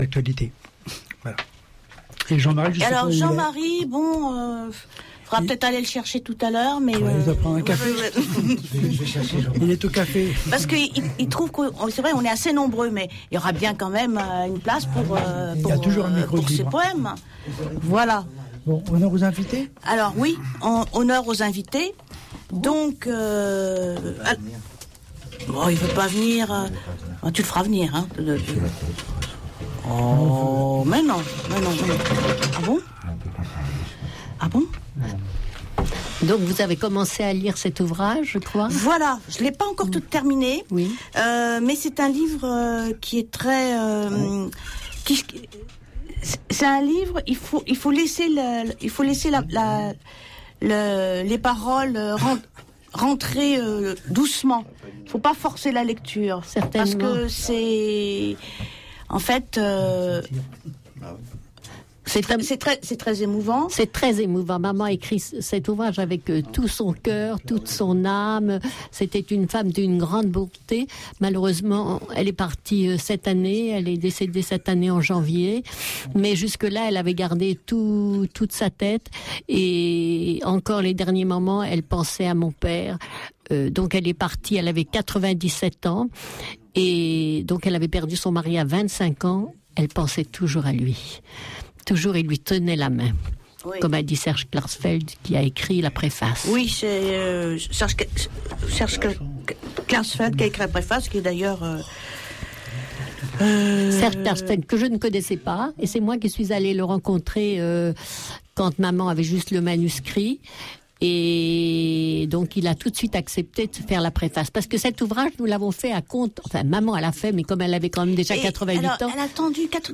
4a3a4-interview-extrait.mp3